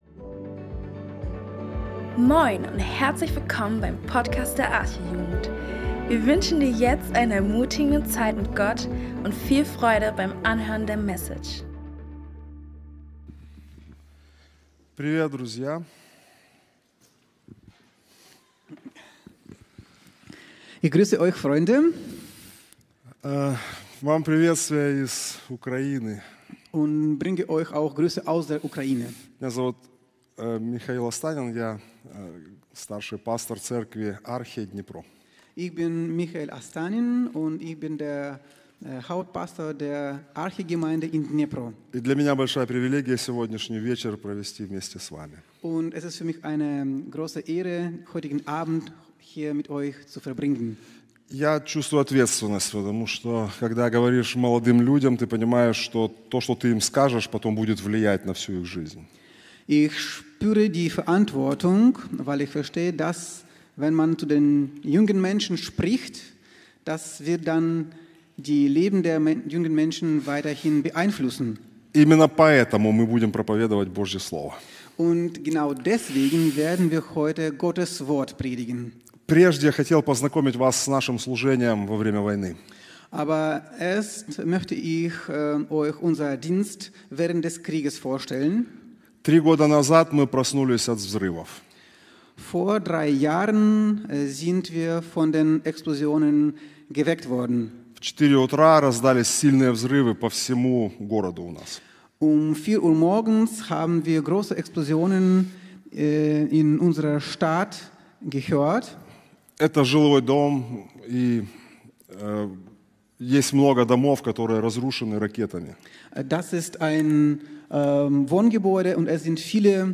Jesus hat mit seinen Jüngern oft darüber gesprochen, dass diese Welt nicht bestehen bleibt (z.B. Matthäus 7,24-27). In dieser Predigt